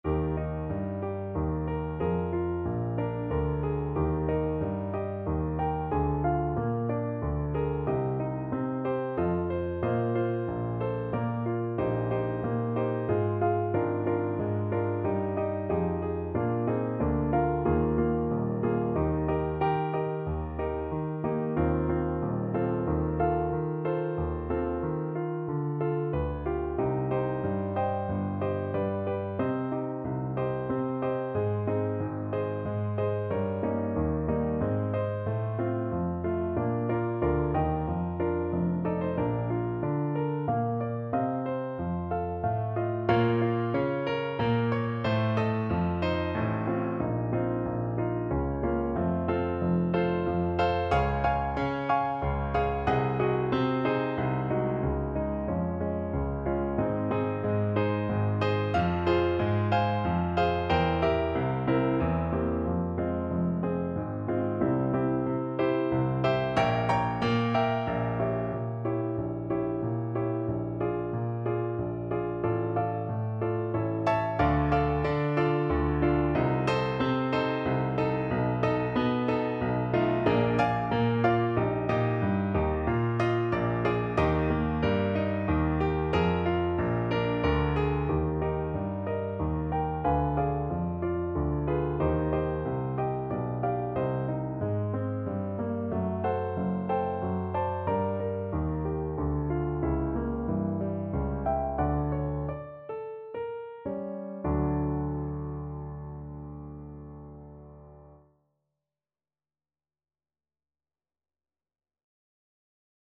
Play (or use space bar on your keyboard) Pause Music Playalong - Piano Accompaniment Playalong Band Accompaniment not yet available reset tempo print settings full screen
Eb major (Sounding Pitch) (View more Eb major Music for Flute )
3/4 (View more 3/4 Music)
~ = 92 Larghetto
Classical (View more Classical Flute Music)